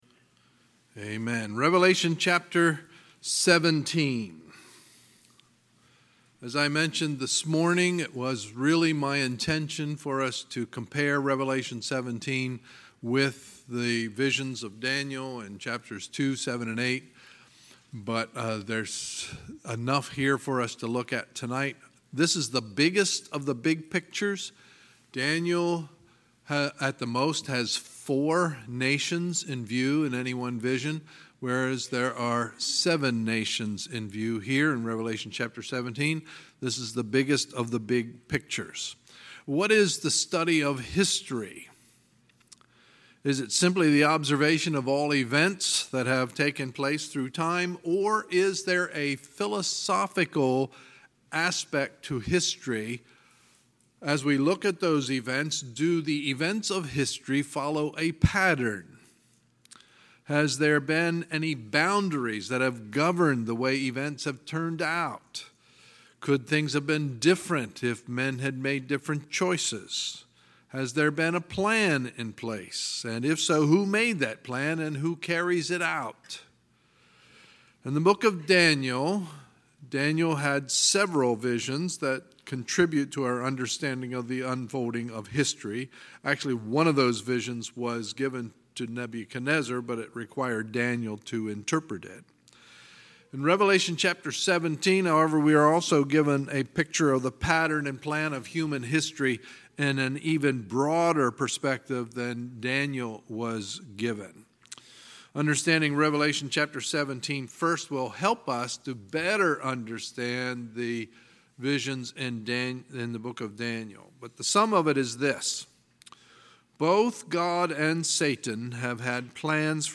Sunday, July 8, 2018 – Sunday Evening Service
Sermons